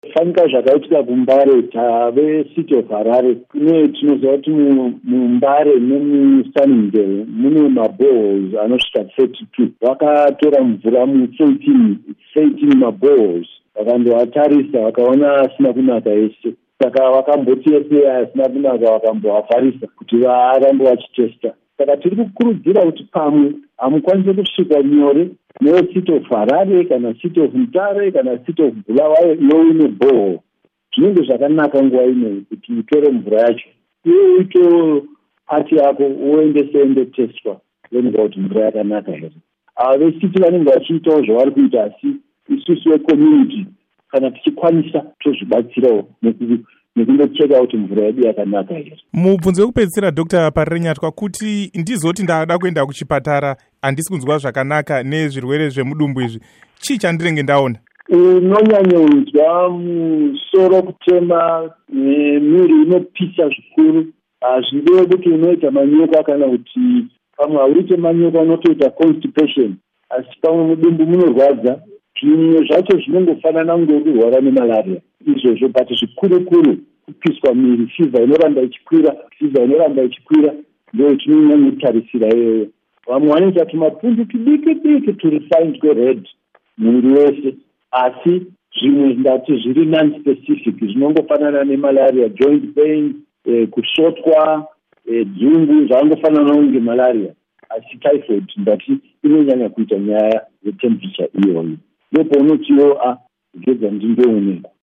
Hurukuro naDr David Parirenyatwa